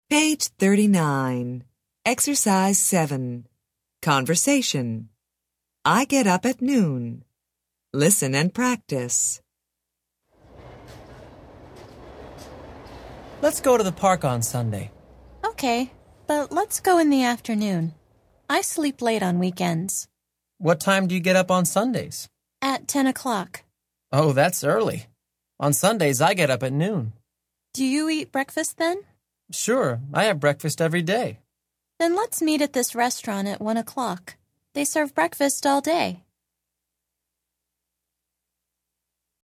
Interchange Third Edition Intro Unit 6 Ex 7 Conversation Track 17 Students Book Student Arcade Self Study Audio